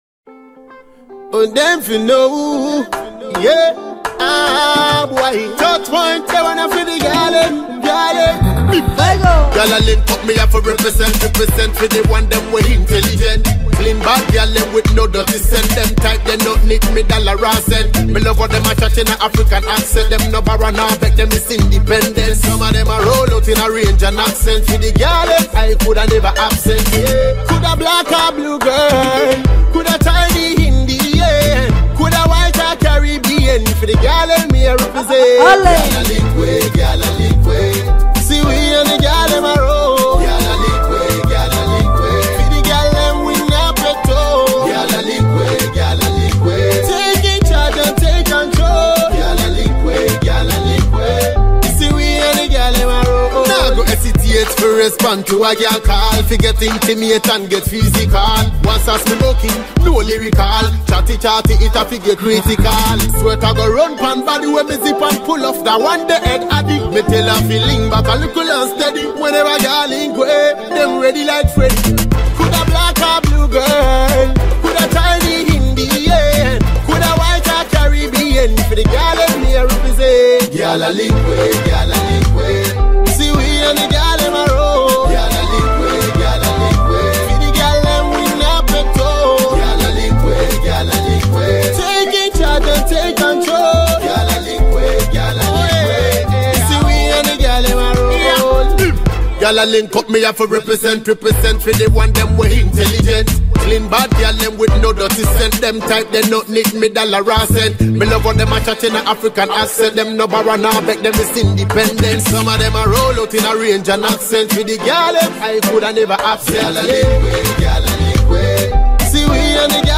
Its a Solid Dancehall Track